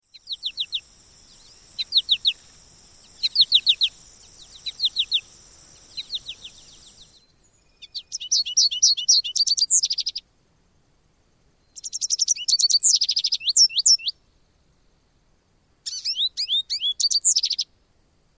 american-goldfinch.mp3